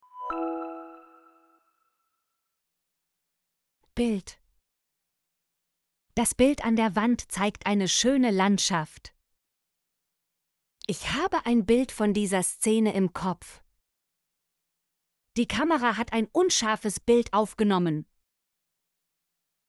bild - Example Sentences & Pronunciation, German Frequency List